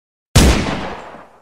دانلود صدای کلت یک تیر از ساعد نیوز با لینک مستقیم و کیفیت بالا
جلوه های صوتی